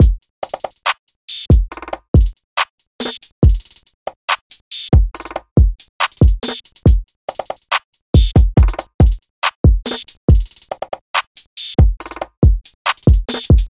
含有808的鼓声循环
Tag: 130 bpm Trap Loops Drum Loops 1.24 MB wav Key : Unknown